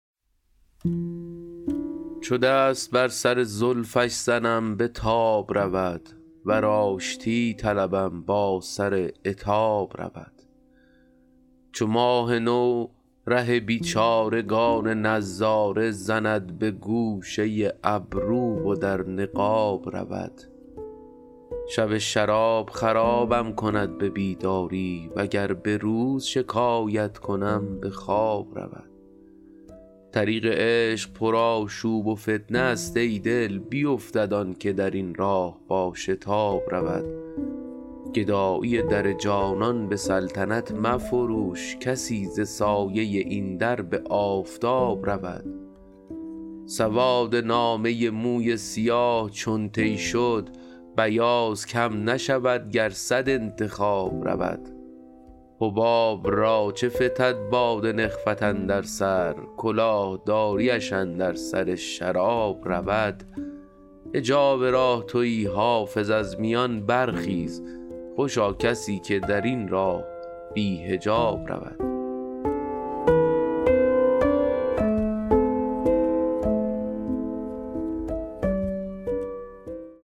حافظ غزلیات غزل شمارهٔ ۲۲۱ به خوانش